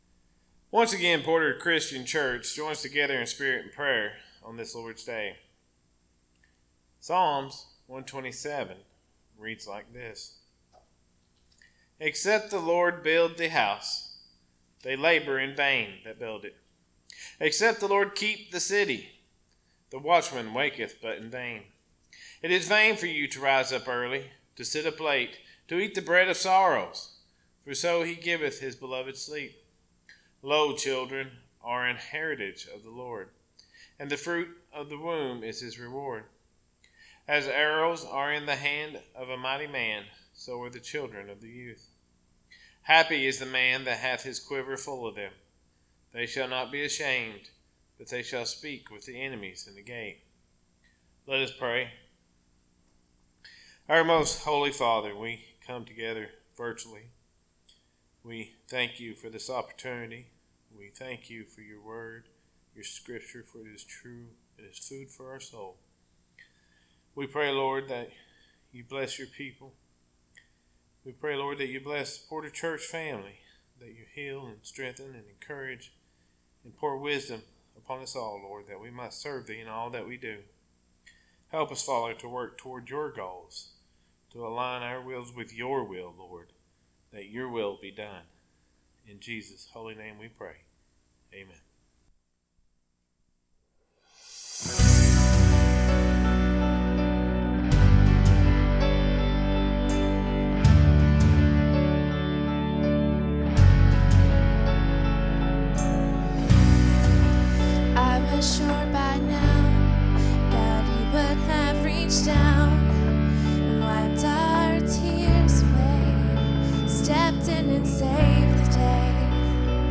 Sermons - Porter Christian Church